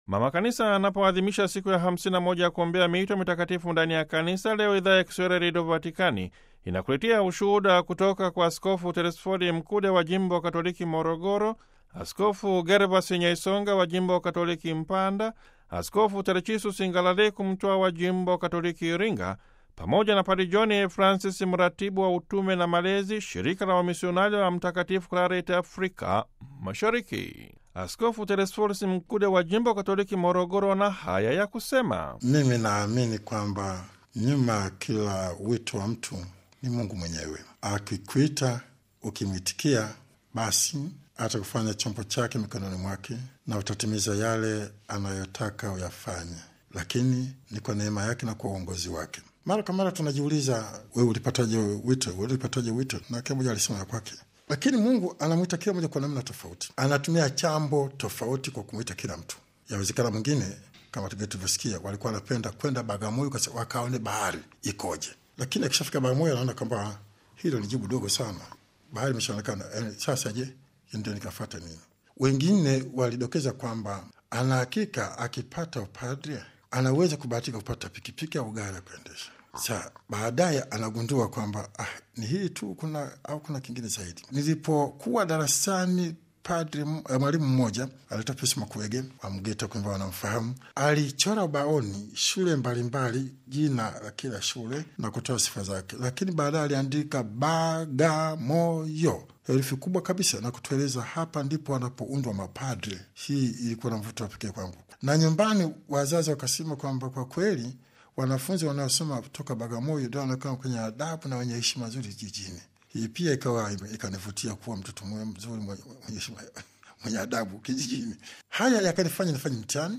Askofu Telesphor Mkude wa Jimbo Katoliki Morogoro katika mahojiano maalum na Radio Vatican anasema kwamba, Mwenyezi Mungu anaendelea kuwaita watu ili kushiriki katika kazi ya kutangaza Habari Njema ya Wokovu hadi miisho ya dunia, kwa kuwajalia neema na baraka wanazohitaji katika utekelezaji wa dhamana hii.